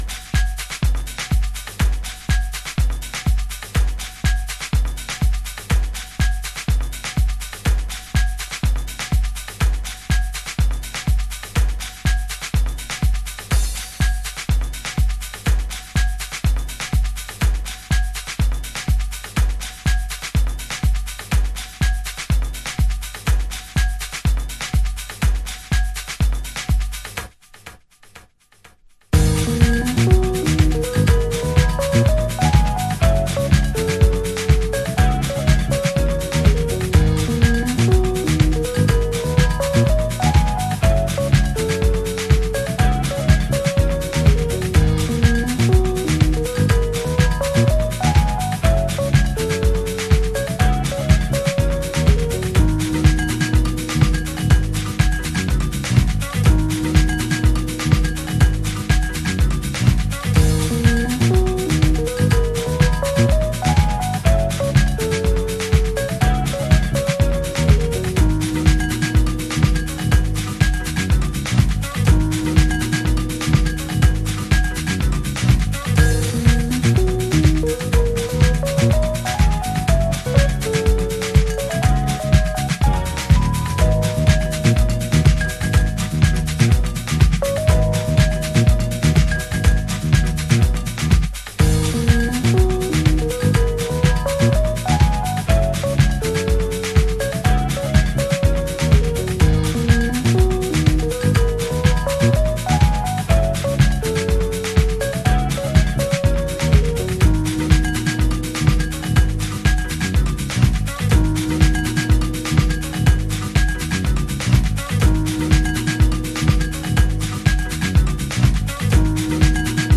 なんとも爽快なブラジリアン・フュージョングルーヴ。